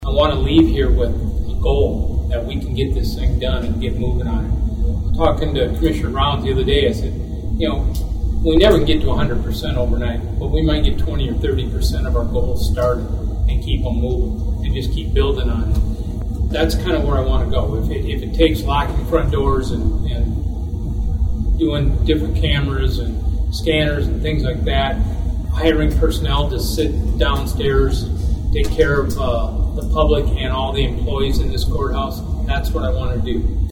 Hughes County Commission chair Rob Fines wants to see something come from recent meetings on the issue.